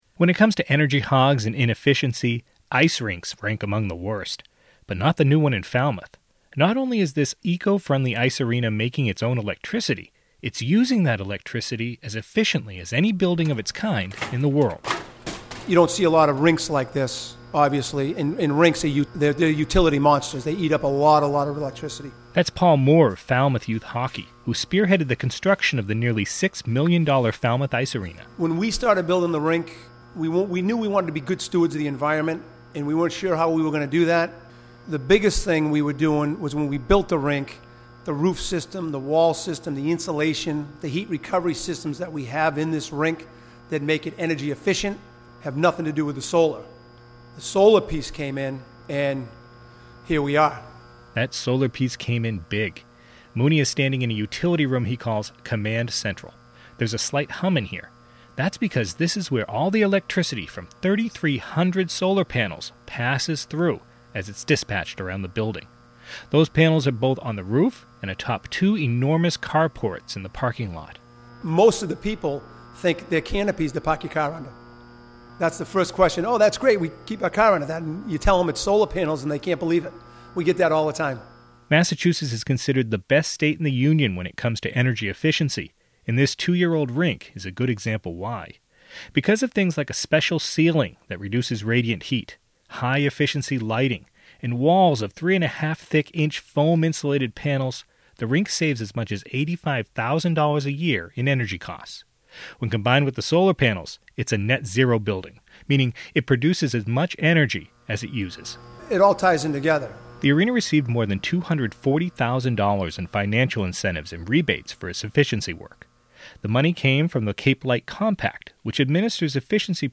There's a slight hum in here.